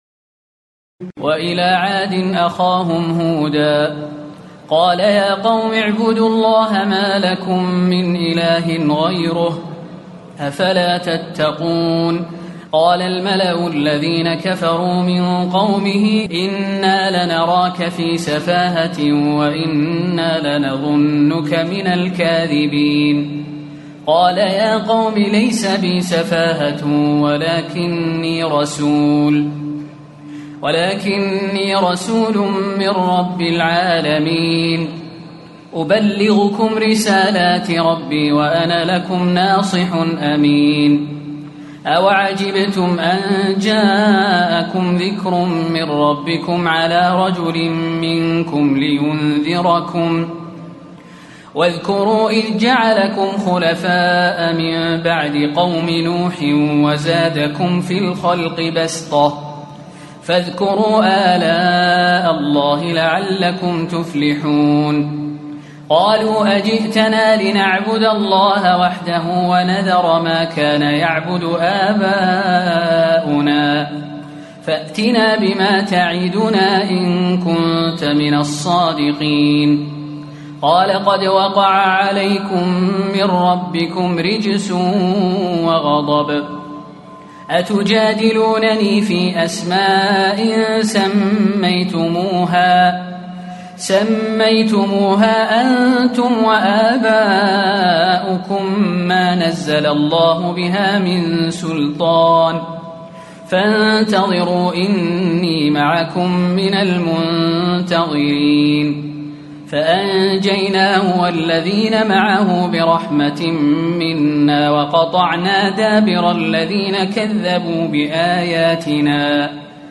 تراويح الليلة الثامنة رمضان 1437هـ من سورة الأعراف (65-154) Taraweeh 8 st night Ramadan 1437H from Surah Al-A’raf > تراويح الحرم النبوي عام 1437 🕌 > التراويح - تلاوات الحرمين